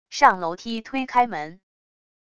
上楼梯推开门wav音频